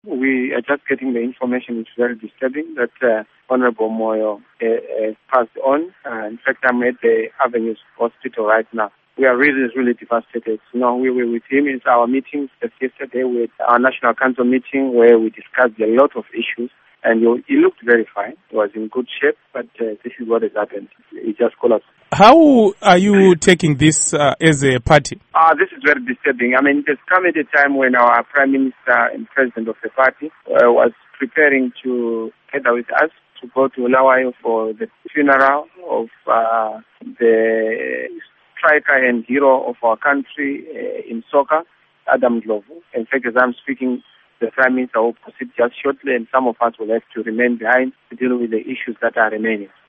Interview With Nelson Chamisa